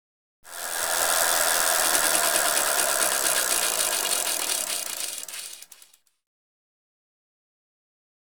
Bicycle Hand Break Stop Sound
transport
Bicycle Hand Break Stop